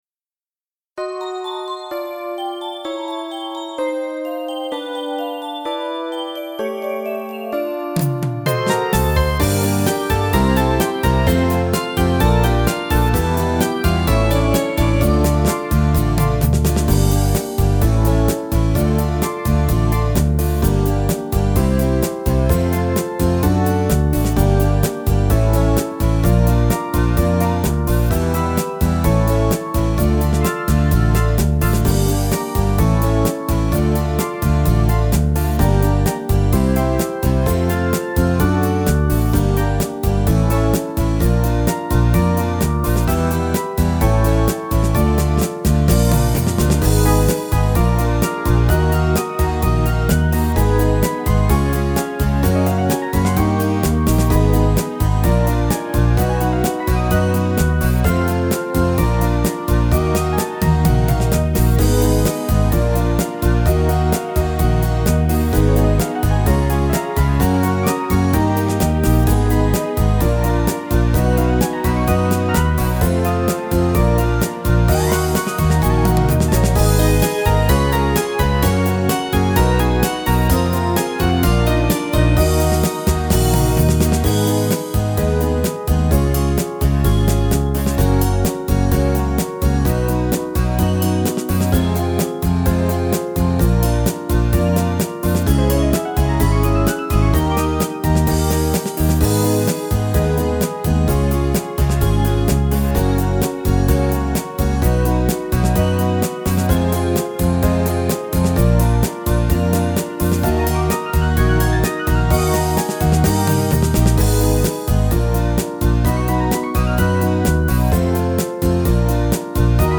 караоке
Скачать минус детской песни